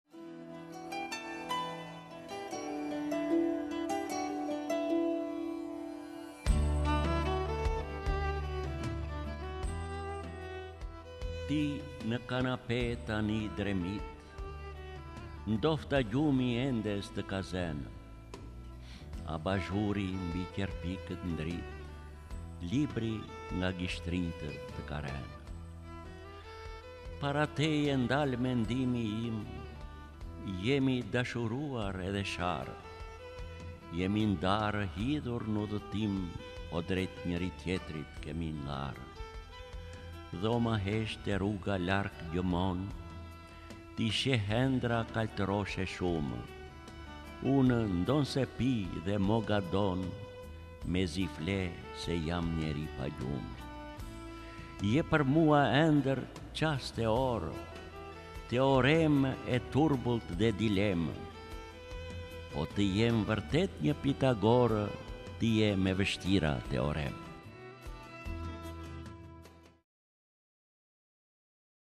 D. AGOLLI - DASHURI E VËSHTIRË Lexuar nga D. Agolli KTHEHU...